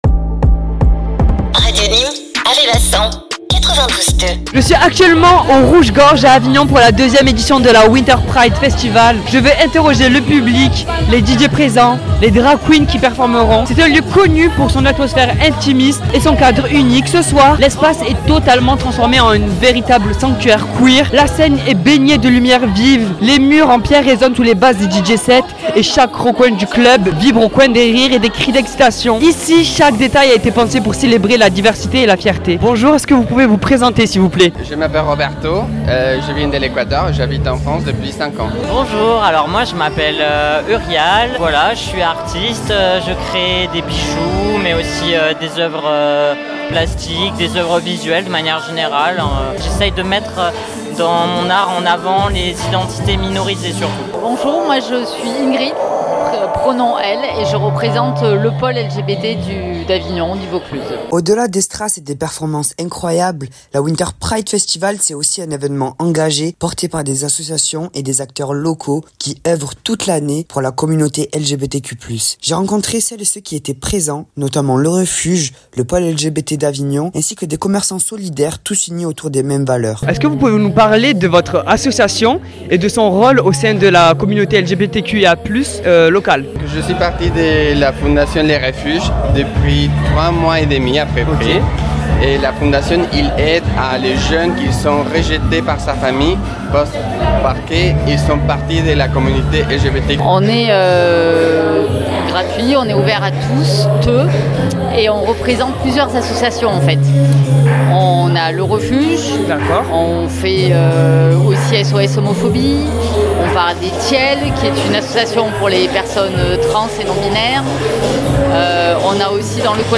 WINTER PRIDE FESTIVAL - REPORTAGE
Interviews des associations, des artistes qui ont mis le feu et des moments de partage avec le public, on vous fait revivre la soirée de l'intérieur.
Un reportage sincère qui vous fera sentir l’ambiance de cette soirée unique et les messages qui ont résonné toute la nuit !